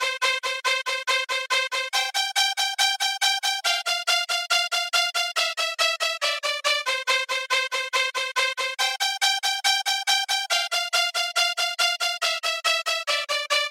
Combo Strings 3
标签： 140 bpm Breakbeat Loops Strings Loops 1.15 MB wav Key : Unknown
声道立体声